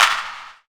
VEC3 Claps 040.wav